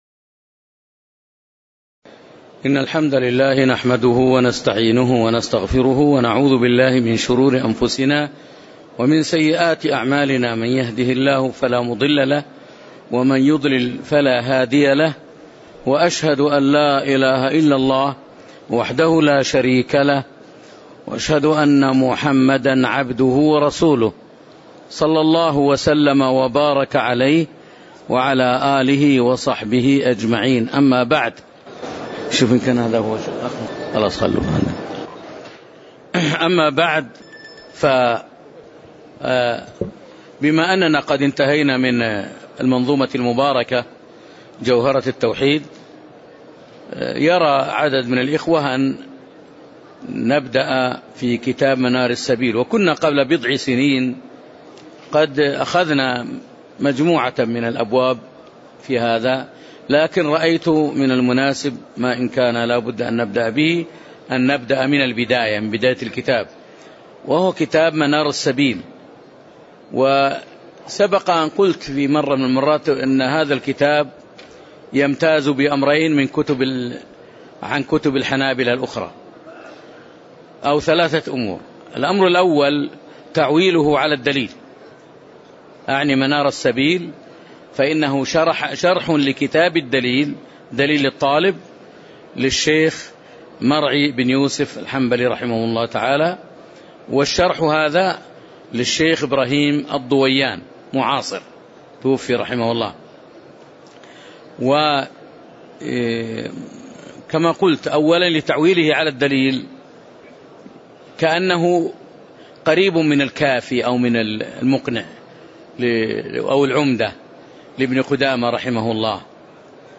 تاريخ النشر ١٨ جمادى الأولى ١٤٣٨ هـ المكان: المسجد النبوي الشيخ